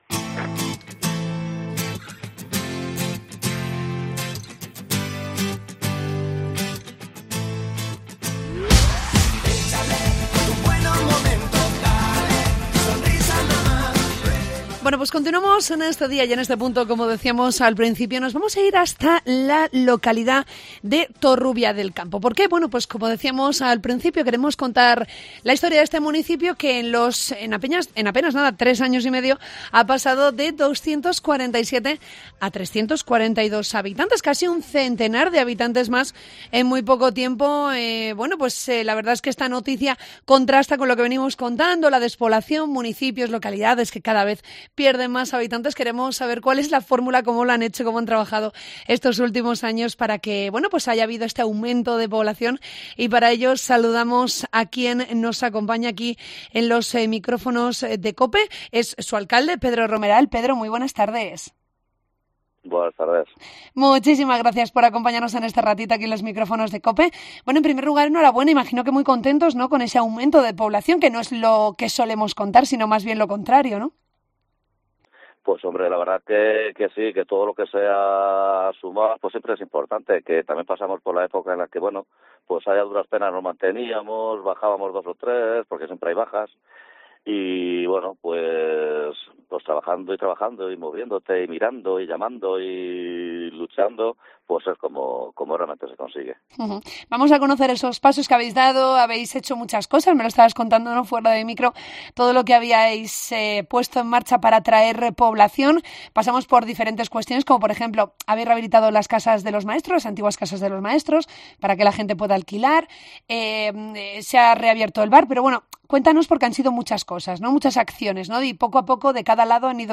En esa lucha contra la despoblación, en COPE hemos hablado con el alcalde de Torrubia del Campo, Pedro Romeral, para conocer las claves de esta subida en el censo de su localidad.